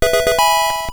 success_small.wav